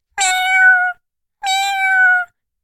cat_meow_normal1.ogg